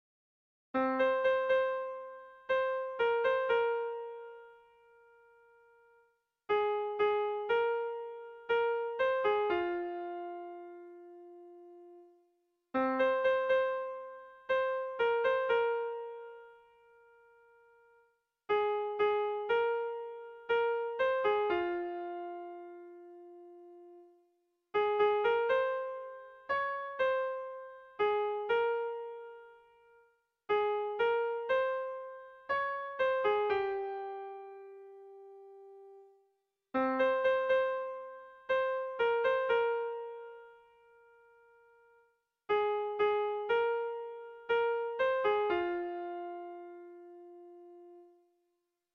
Kontakizunezkoa
Zortziko ertaina (hg) / Lau puntuko ertaina (ip)
AABA